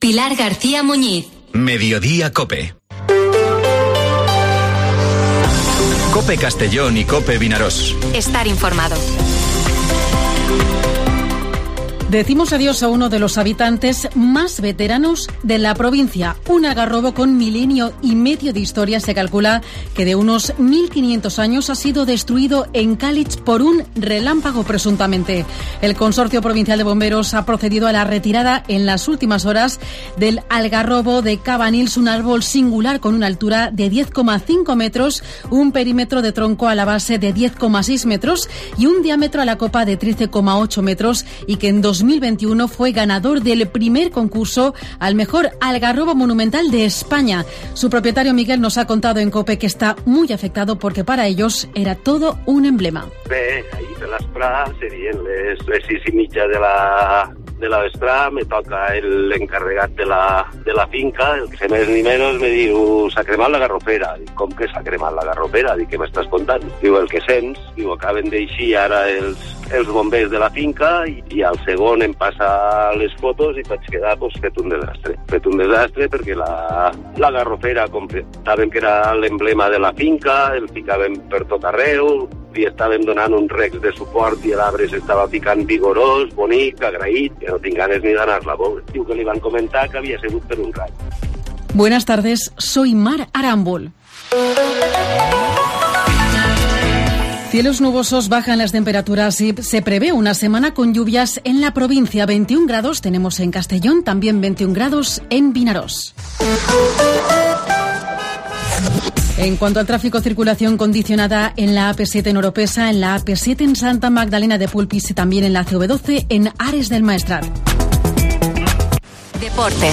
Informativo Mediodía COPE en la provincia de Castellón (22/05/2023)